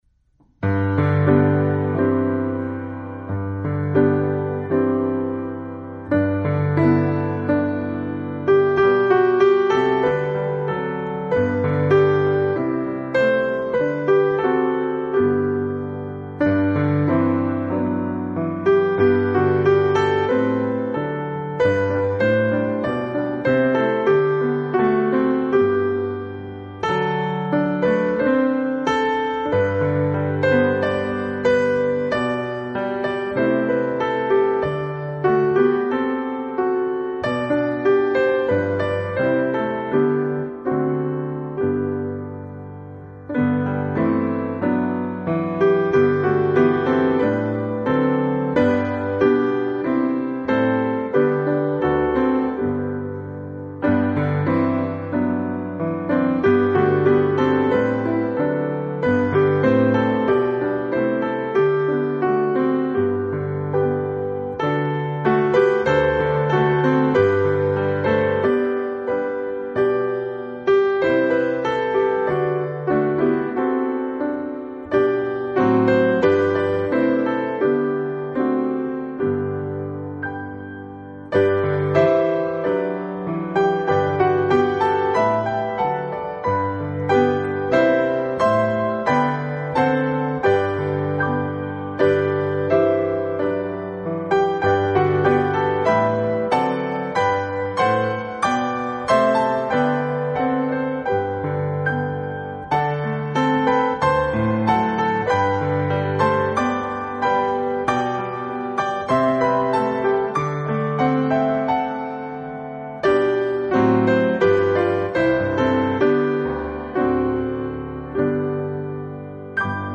This 5-day devotional, based on Hosannas Forever by David and Barbara Leeman, features five timeless hymns—each paired with a brief reflection and the story behind its lyrics and melody.